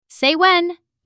/seɪ wen/【イディオム】（飲み物などを注ぐ時に）いいところで言ってね！